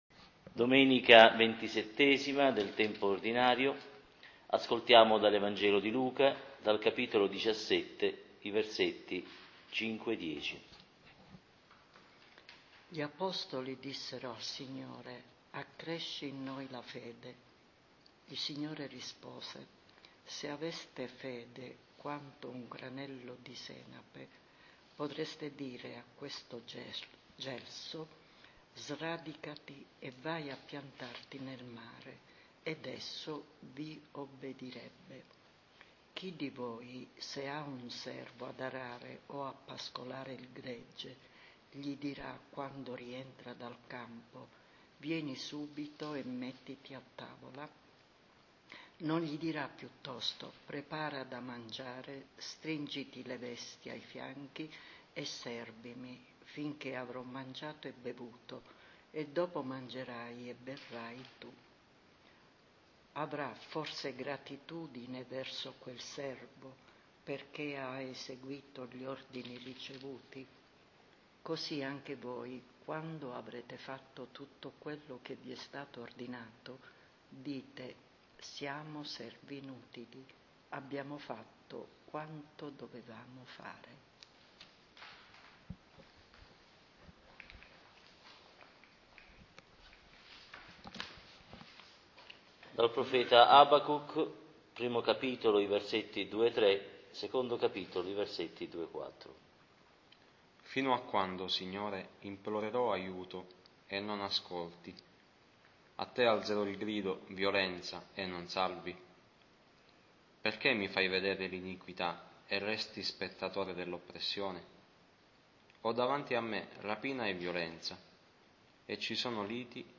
Lectio divina DOMENICA «DEI SERVI INUTILI», XXVII del Tempo per l’Anno C - Abbazia di Pulsano.